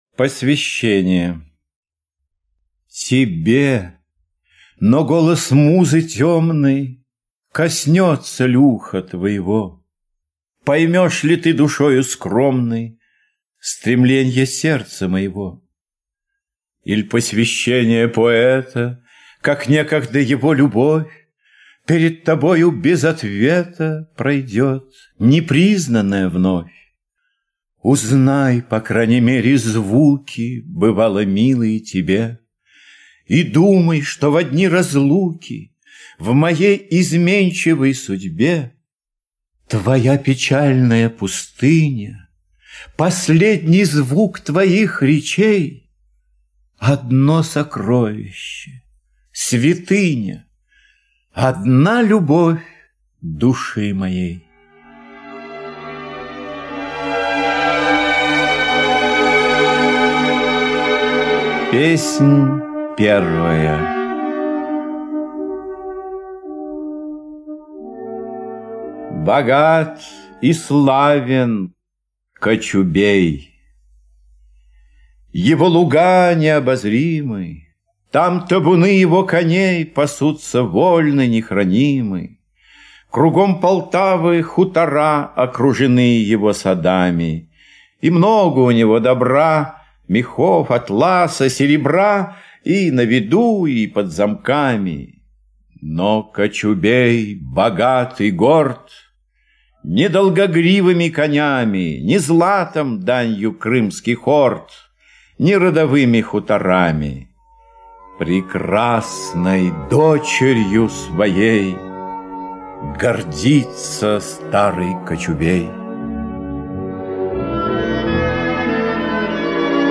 Полтава - аудио поэма Пушкина А.С. Поэма о Полтавской битве - одного из самых важных и счастливых моментов царствования Петра Великого.